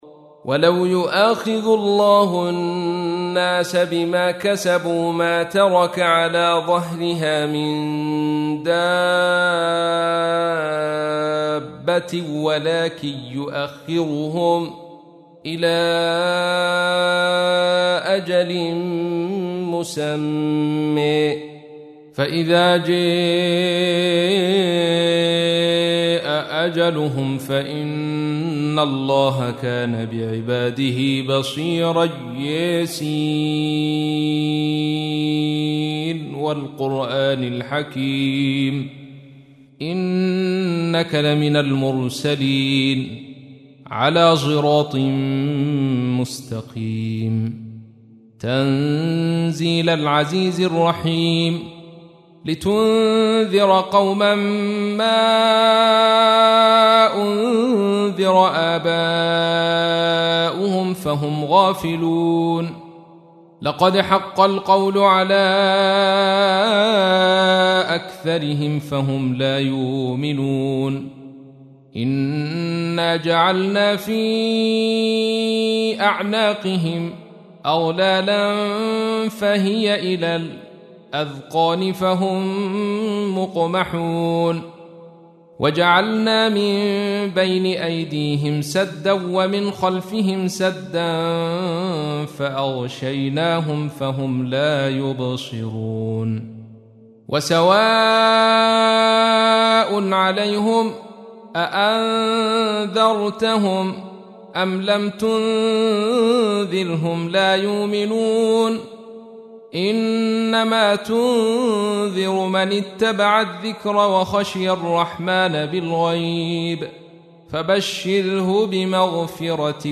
تحميل : 36. سورة يس / القارئ عبد الرشيد صوفي / القرآن الكريم / موقع يا حسين